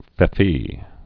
(fĕf-ē, fē-fē)